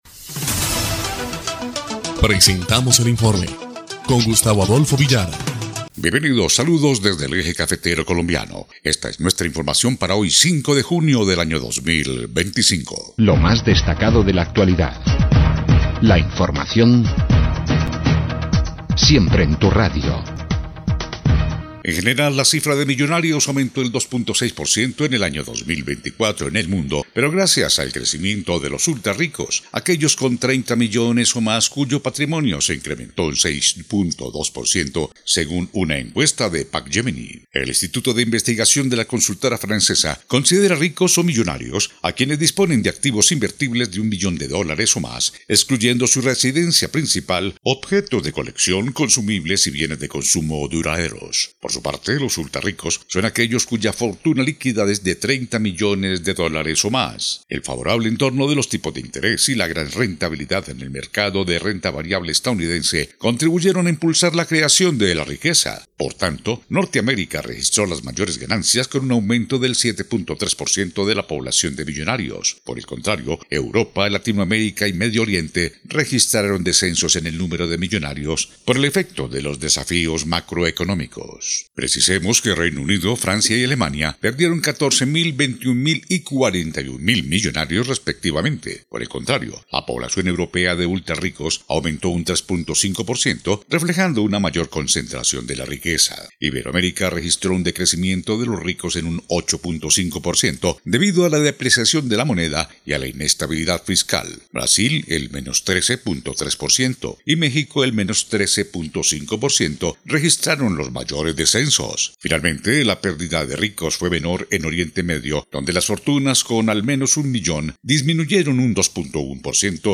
EL INFORME 2° Clip de Noticias del 5 de junio de 2025